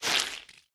Minecraft Version Minecraft Version latest Latest Release | Latest Snapshot latest / assets / minecraft / sounds / block / sponge / absorb1.ogg Compare With Compare With Latest Release | Latest Snapshot
absorb1.ogg